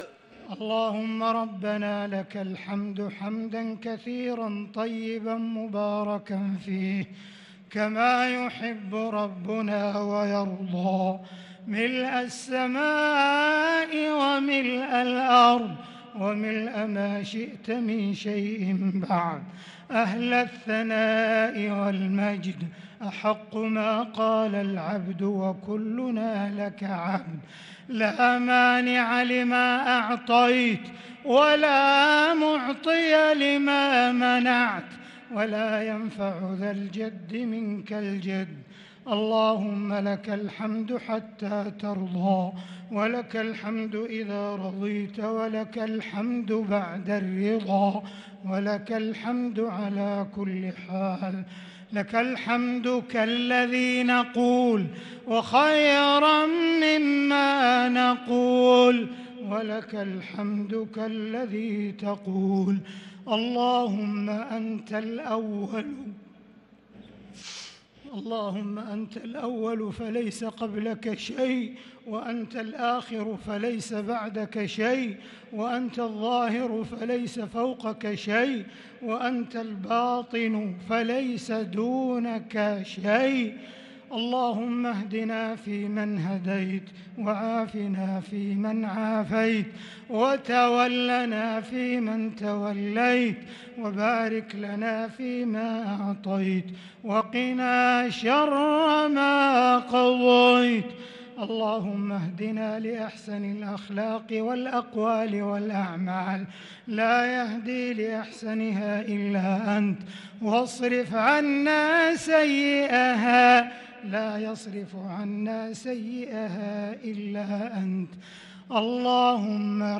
دعاء القنوت ليلة 16 رمضان 1443هـ | Dua for the night of 16 Ramadan 1443H > تراويح الحرم المكي عام 1443 🕋 > التراويح - تلاوات الحرمين